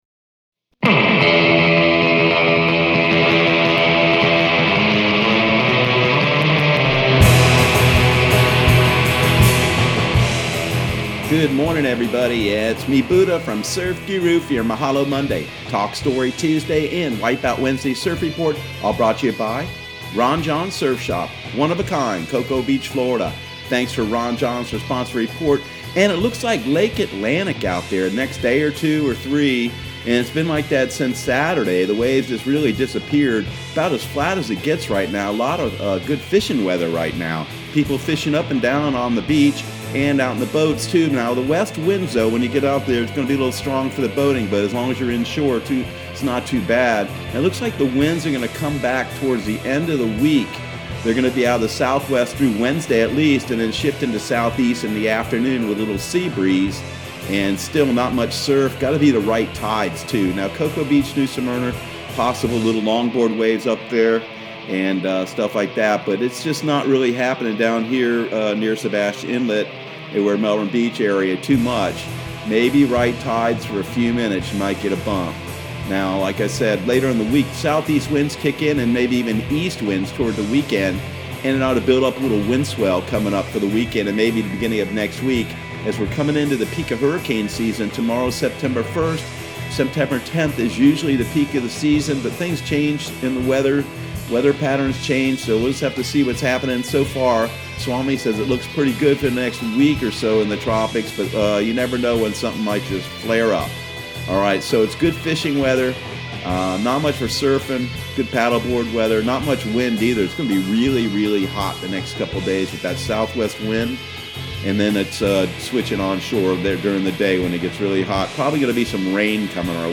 Surf Guru Surf Report and Forecast 08/31/2020 Audio surf report and surf forecast on August 31 for Central Florida and the Southeast.